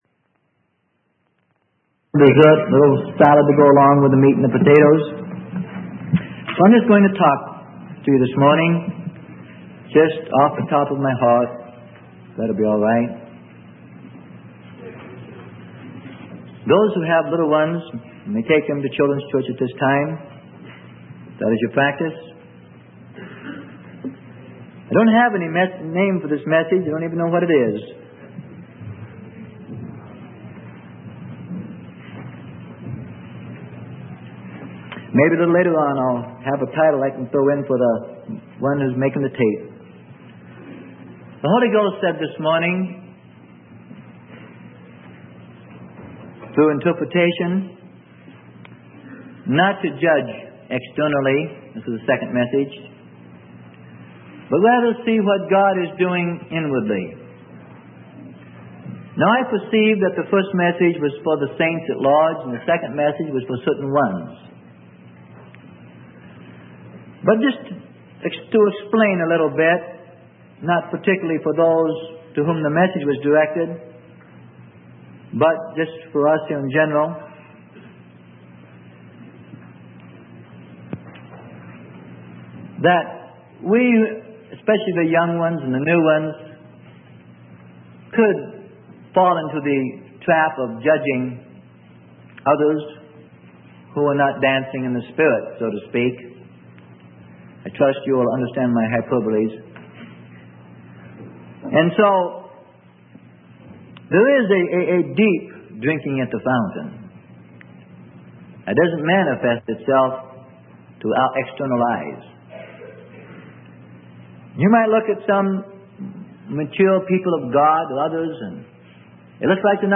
Sermon: Moving of the Spirit - Freely Given Online Library